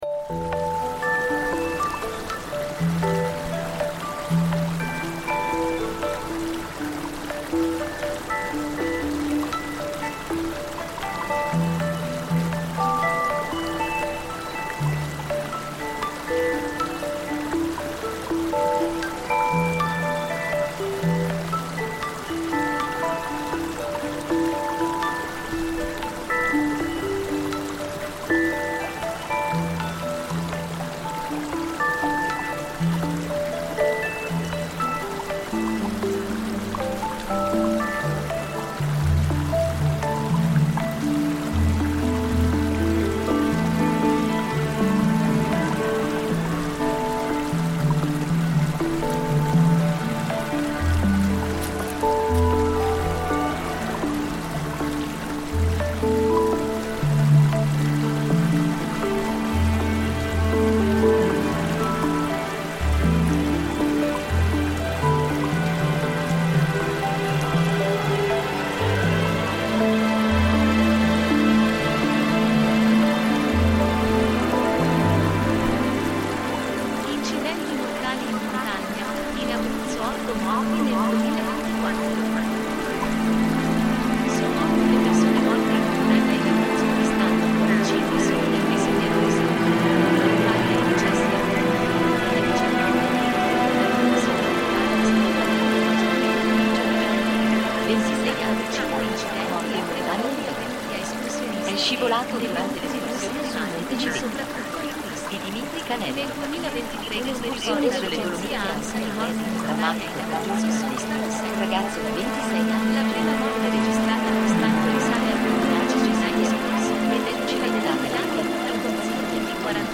Mountain stream at Monte Civetta reimagined